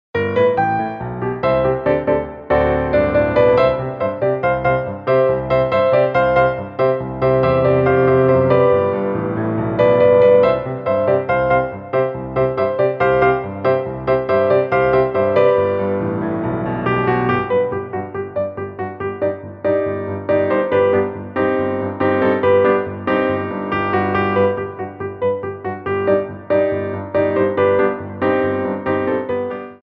Piano Arrangements
Jetés
2/4 (16x8)